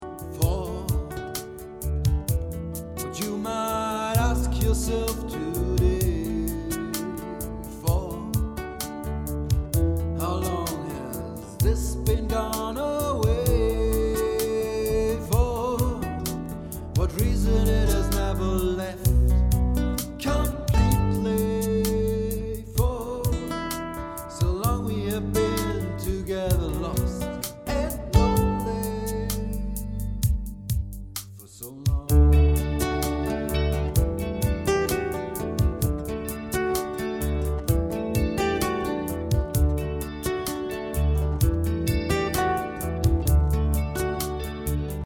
drums: QY-20